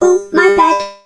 nani_kill_vo_02.ogg